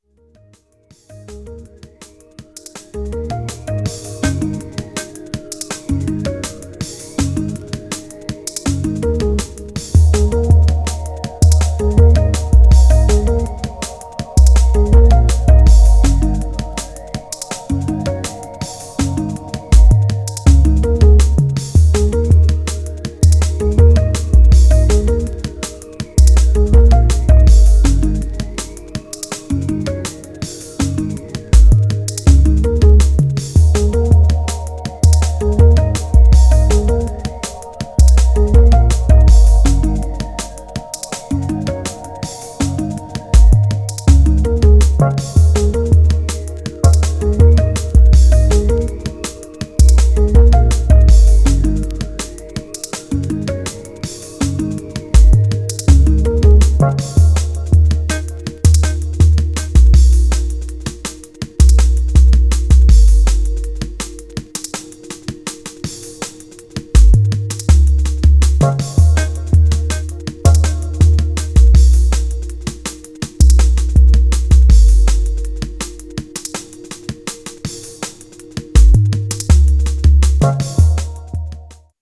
Styl: Drum'n'bass, Jungle/Ragga Jungle